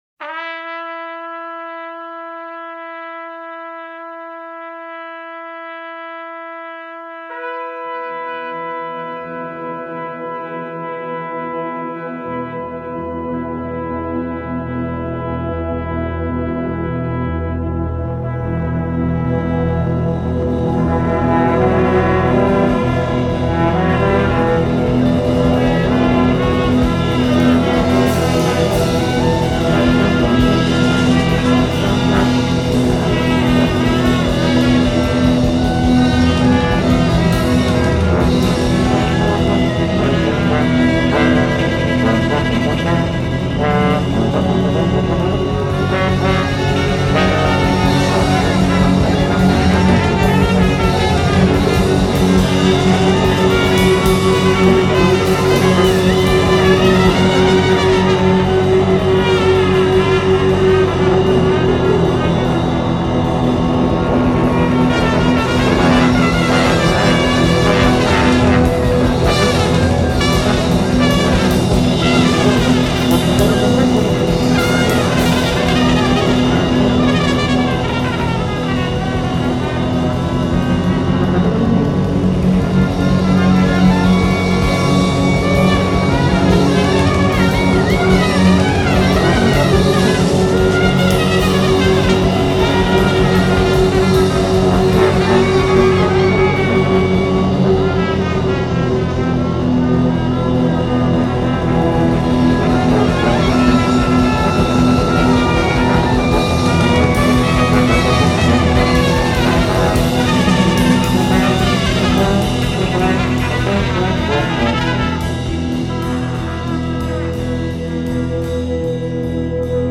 Post rock | Shoegazing